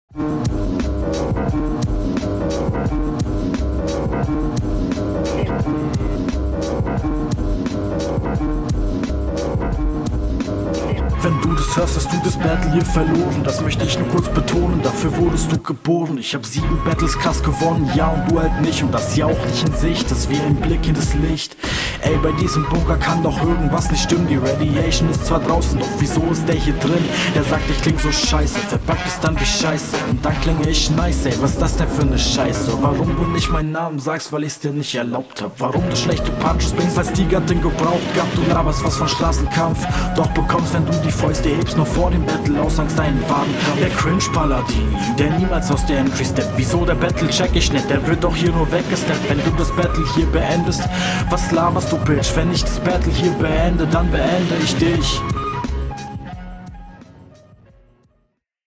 du klingst leider nicht so nice auf dem beat. stimmeinsatz lässt zu wünschen übrig und …